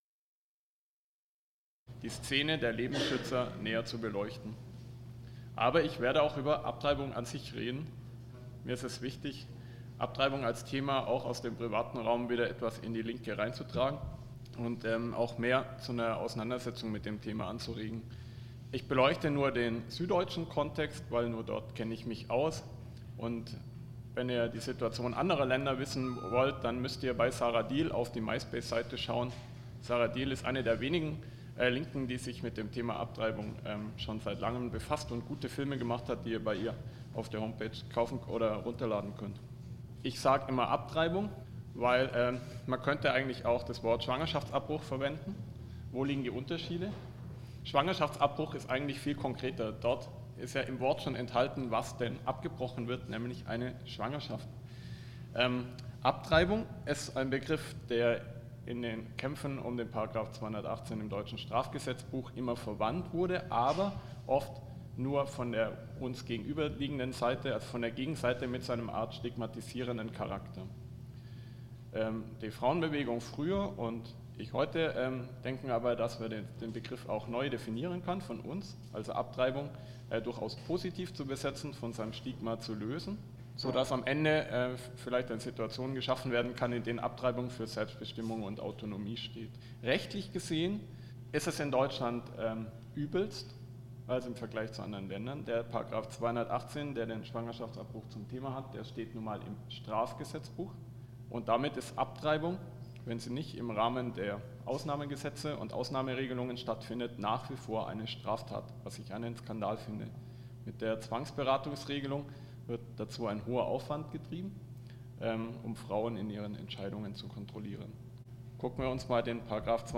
Zusammenfassung eines Vortrages
der auf dem Antifa-Camp des AABW am letzten Wochenende gehalten wurde. Thema des Vortrages ist der Kampf um ein Recht auf Abtreibung in Deutschland, sowie Aufklärung über die krude Ideologie der Lebensschutz-Szene.